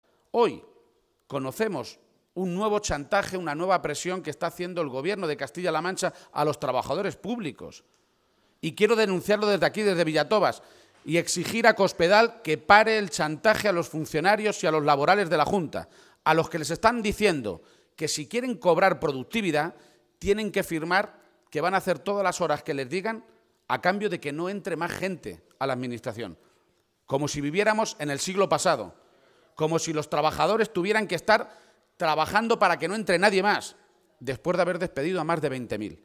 García-Page, que ha participado esta mañana en un acto público con militantes y simpatizantes socialistas en Villatobas (Toledo) ha pedido a Rajoy y Cospedal “que dejen de mentir y de tomar por tontos a los españoles porque cuando hablan de recuperación están hablando solo para uno de cada cuatro ciudadanos de este país, hablan de la recuperación de unos pocos, de aquellos que precisamente especularon y se llenaron los bolsillos a costa de la mayoría de la gente”.